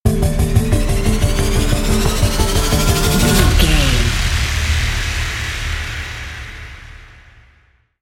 Thriller
Aeolian/Minor
drum machine
synthesiser
electric piano
percussion
tension
ominous
dark
suspense
haunting
creepy